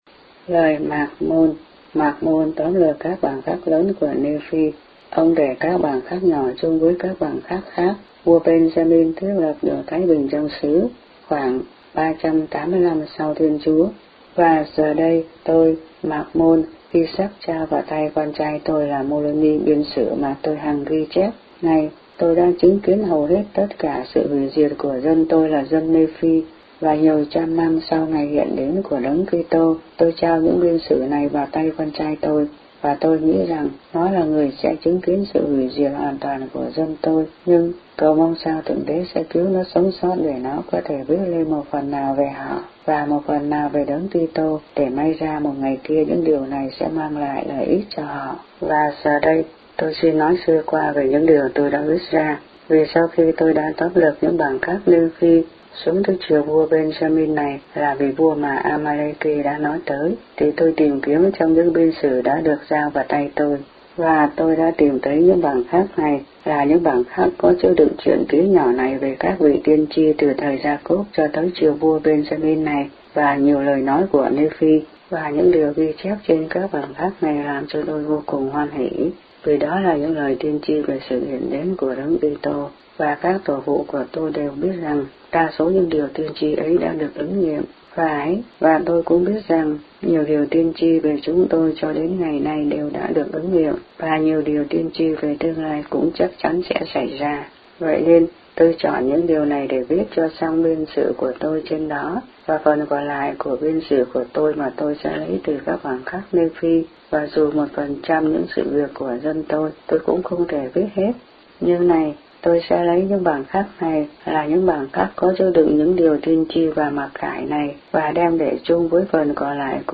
The Book of Mormon read aloud in Vietnamese.